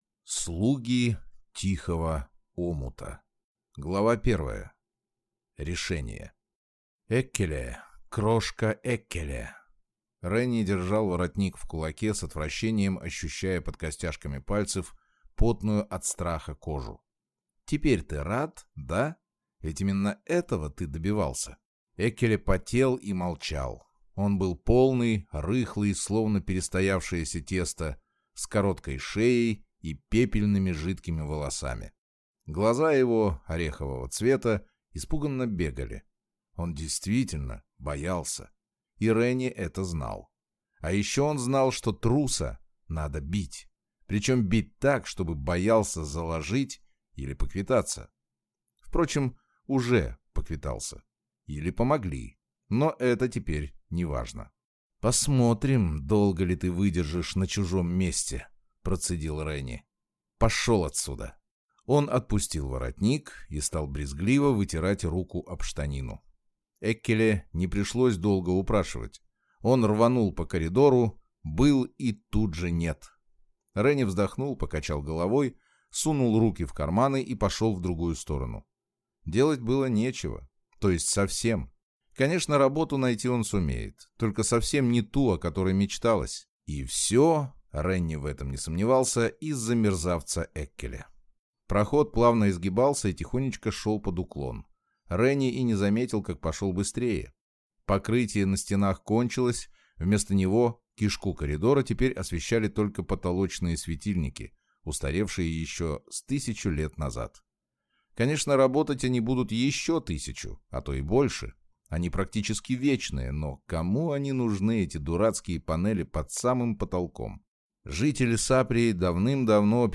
Аудиокнига Слуги тихого омута | Библиотека аудиокниг